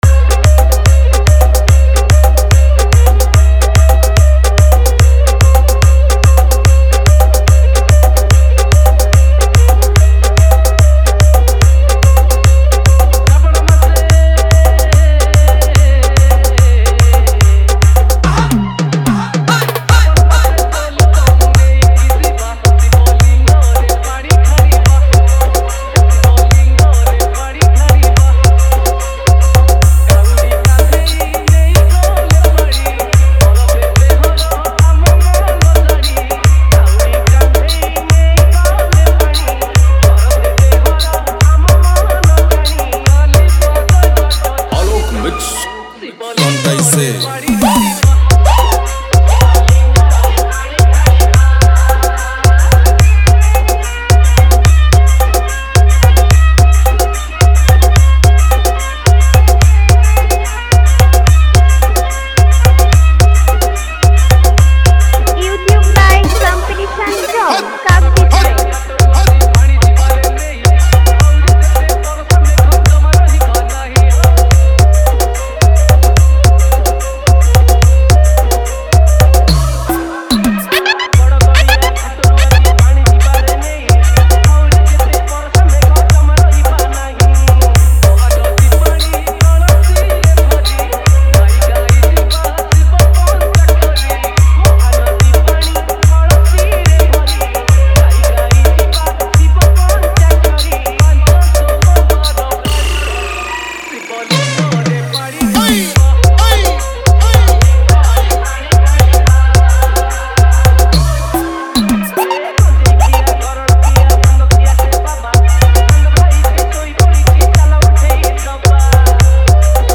Bolbum Special Dj Song
Bhajan Dj Remix